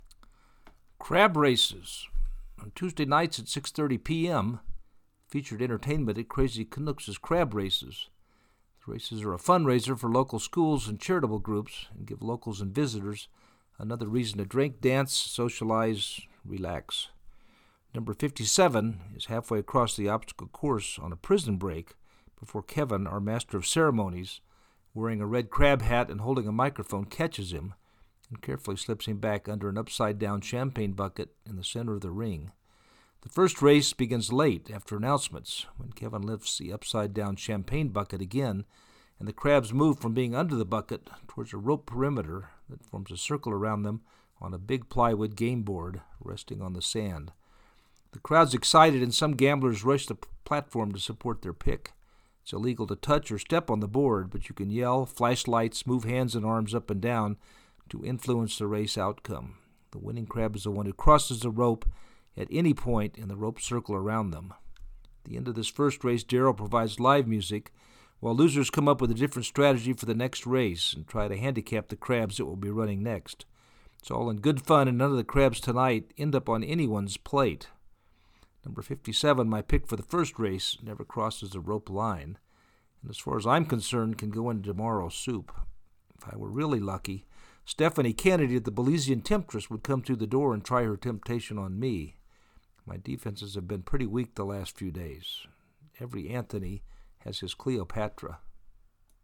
On Tuesday nights, at 6:30 pm, featured entertainment at Crazy Canuck’s is crab races.
The crowd is excited and some gamblers rush the platform to support their pick.